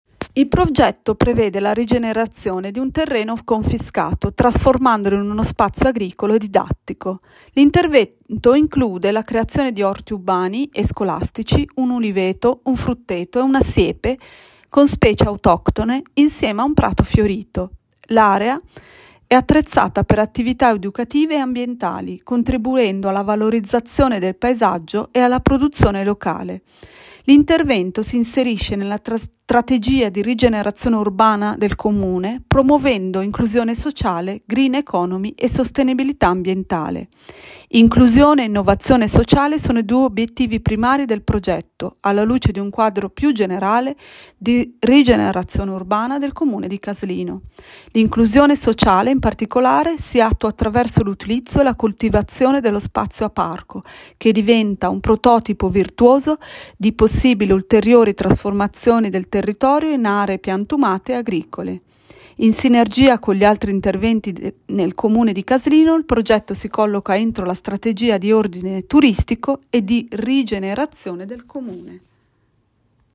Audioguida